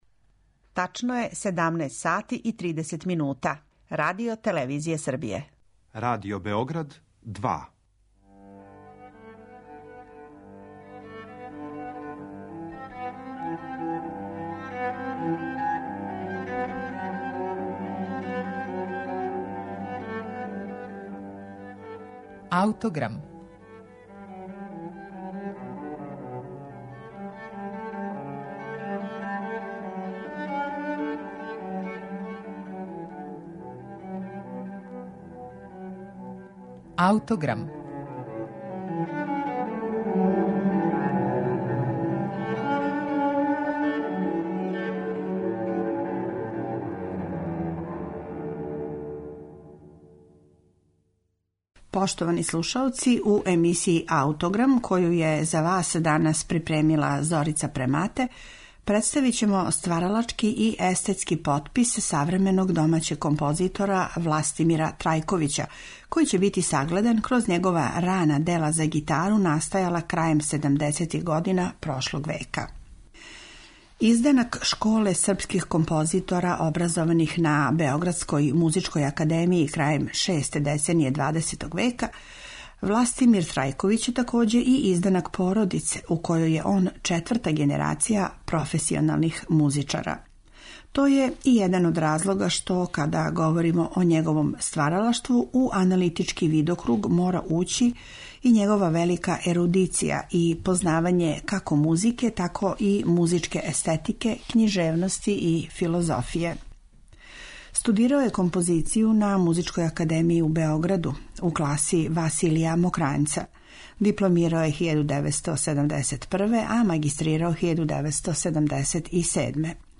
за гитару и гудаче